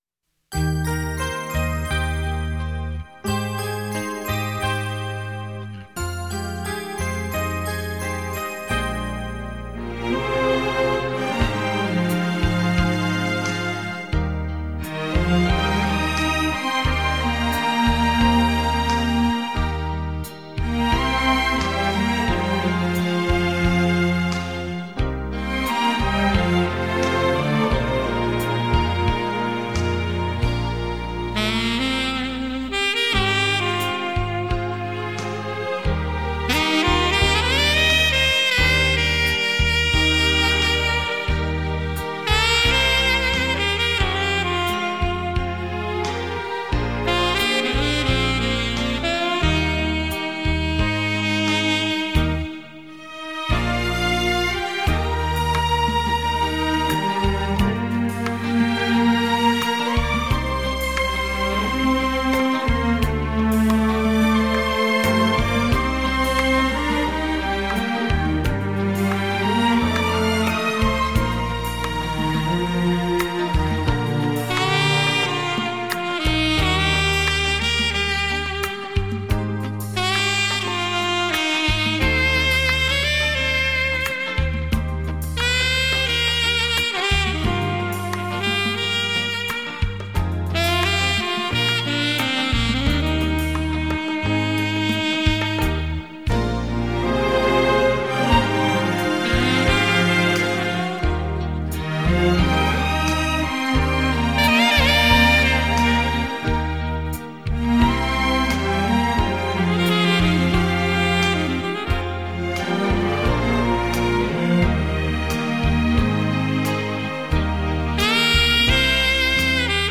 身历其境的临场效果
享受音乐的洗礼身历其境的临场音效